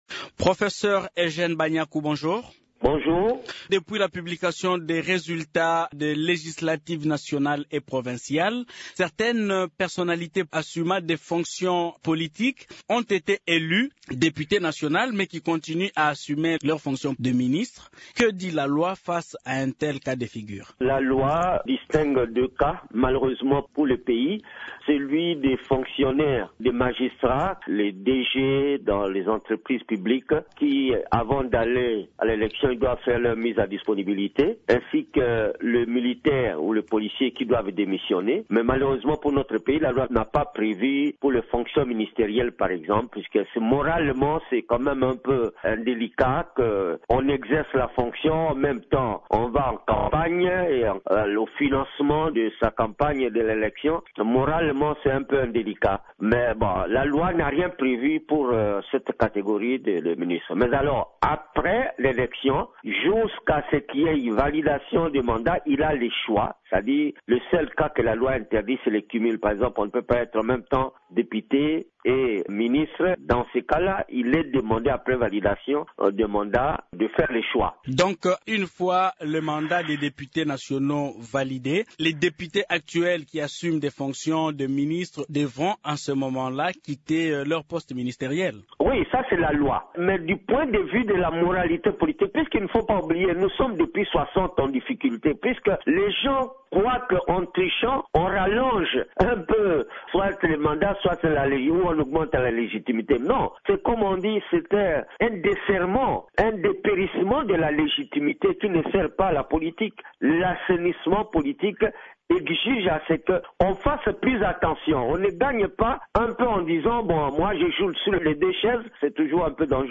Le professeur Eugène Banyaku s’entretient